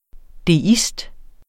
Udtale [ deˈisd ]